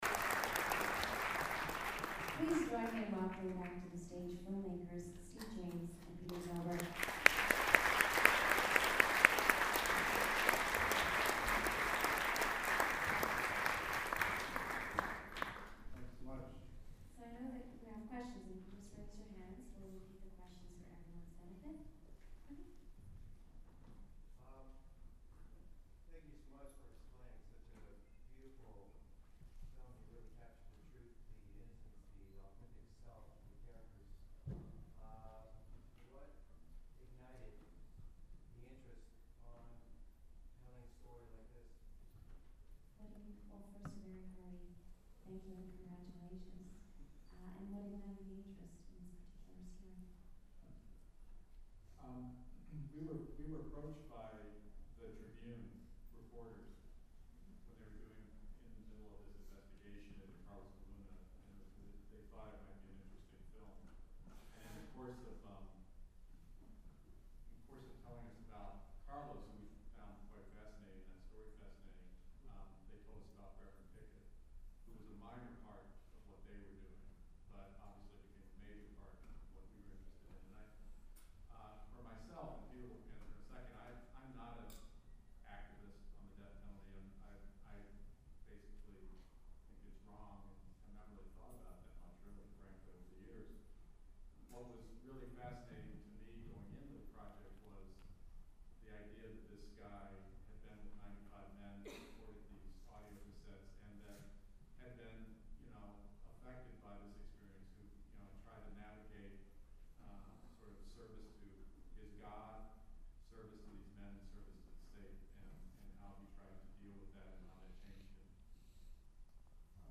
deathhousedoor_qa.mp3